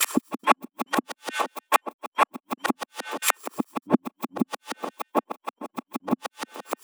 Index of /90_sSampleCDs/Transmission-X/Percussive Loops
tx_perc_140_scrimble.wav